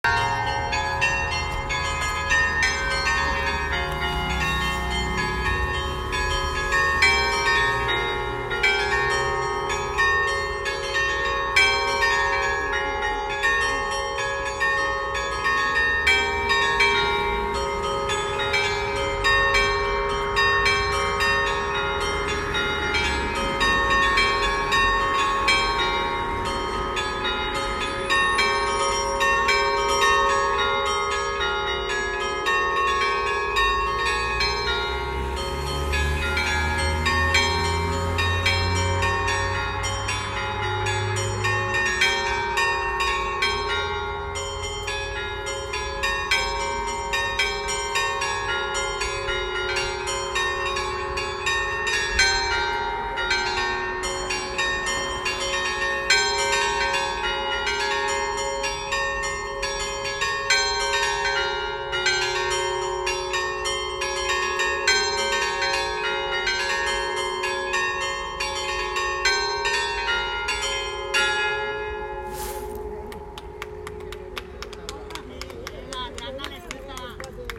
LE CINQUE CAMPANE DELLA CHIESETTA DI S. ANTONIO  IN LOCALITÀ TREDES
Attualmente è stata ripristinata la tastiera con i relativi tiranti per potere suonare in “allegrezza” e diffondere motivetti gioiosi  e festivi.
Campane-s.-Antonio2.m4a